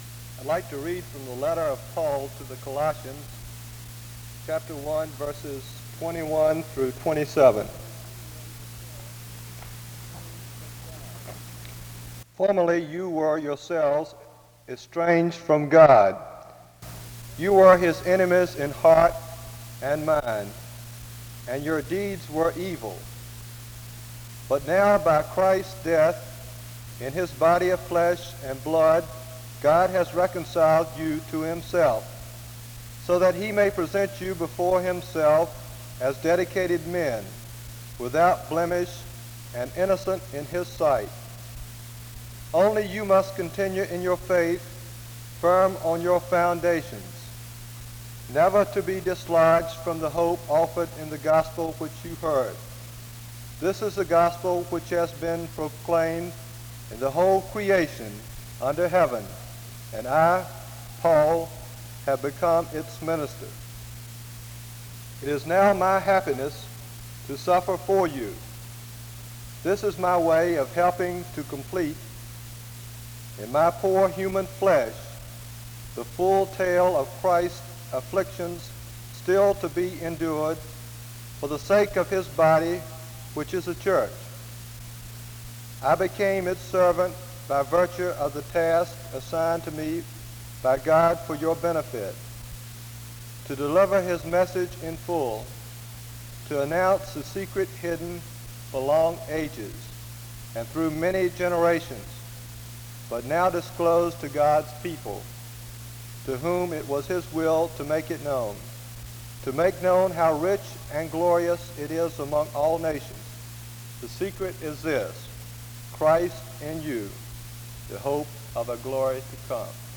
The service begins with the scripture reading of Colossians 1:21-27 from 0:00-2:00. A prayer is offered from 2:01-3:02.